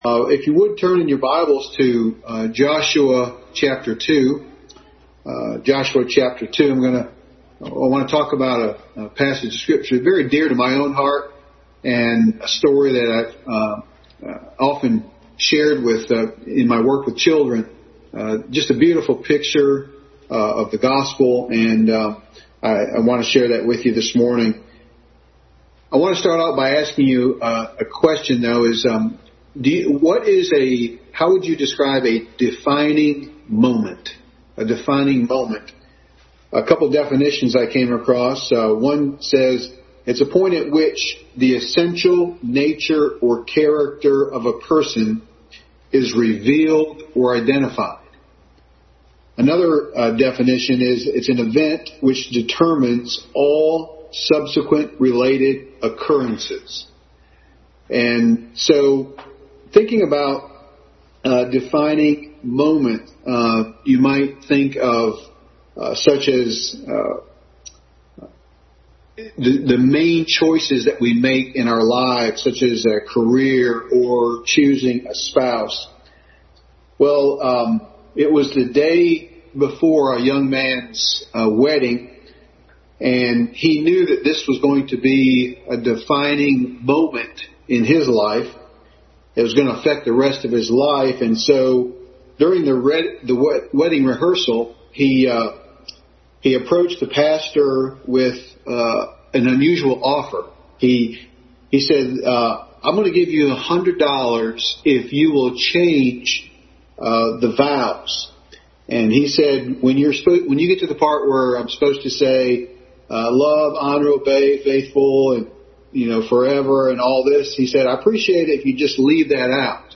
Joshua 2:1-14 Passage: Joshua 2:1-14, Genesis 15:13-16, Hebrews 11:31, James 2:25, Joshua 6:22-25 Service Type: Family Bible Hour Family Bible Hour message.